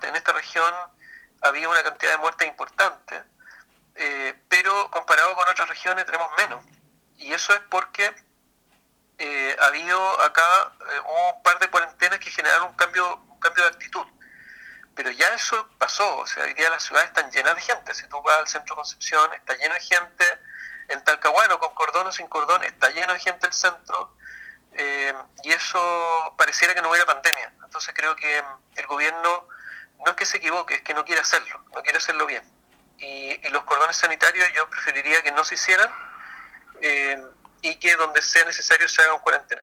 Un llamado a levantar los cordones sanitarios e implementar medidas más estrictas destinadas a impedir la propagación del Covid-19 en la Región del Biobío efectuó el diputado ecologista Félix González en conversación con Nuestra Pauta.